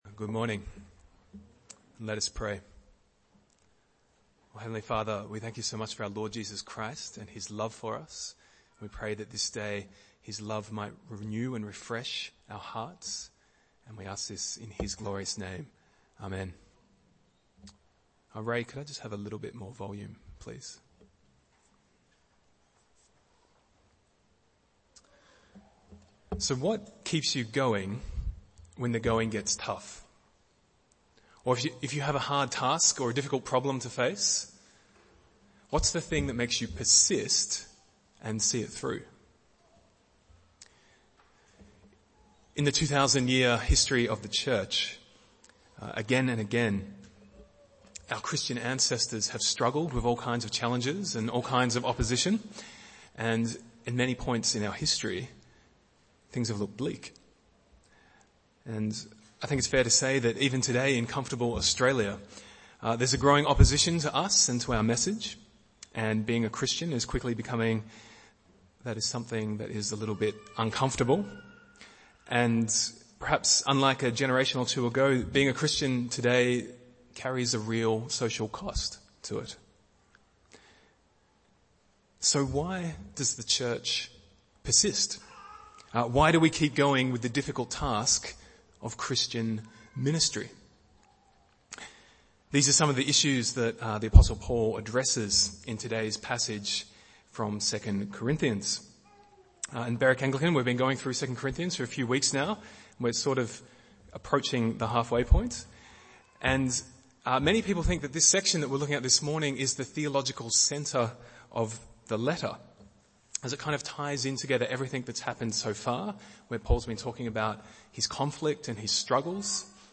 Bible Text: 2 Corinthians 5:11-21 | Preacher